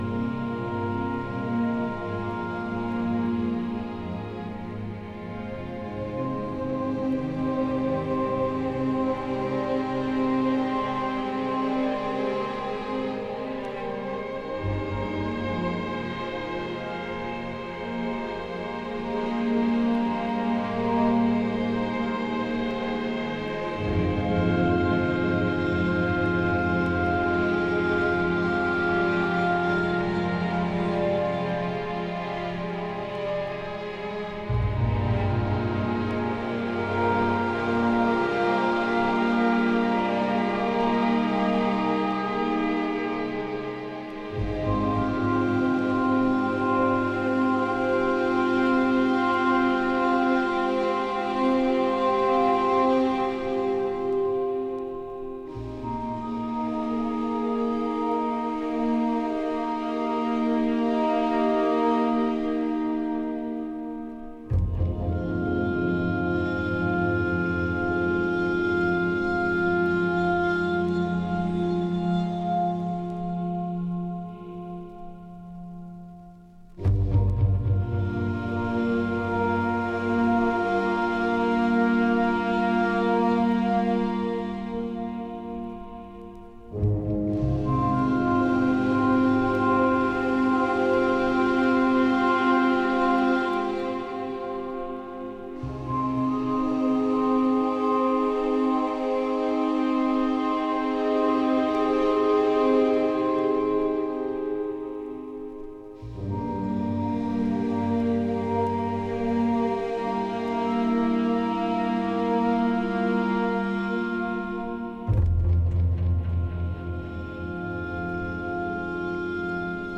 encontramos ainda assim música ambiente forte, emotiva